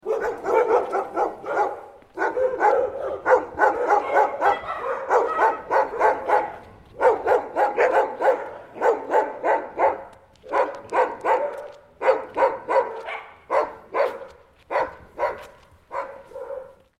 Aggressive-neighbor-dogs-barking-sound-effect.mp3